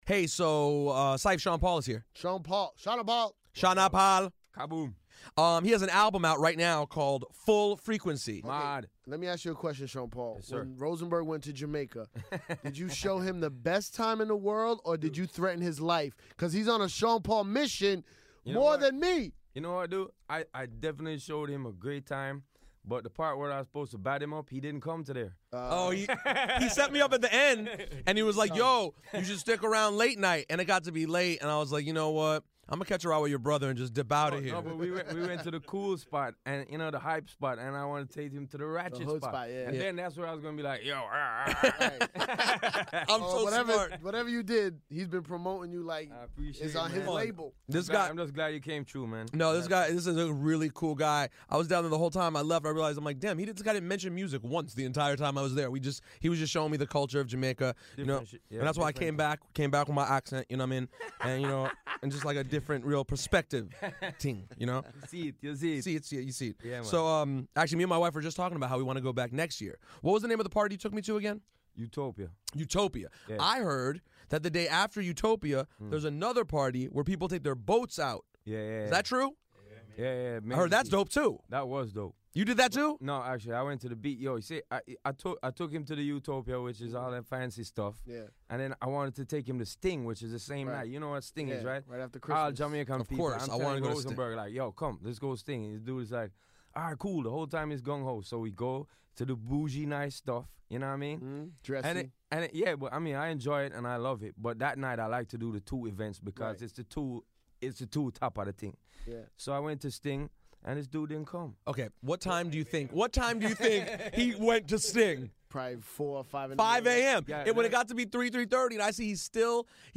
Number 1 — big ups to Sean Paul who came by for this episode too and cohosted with us.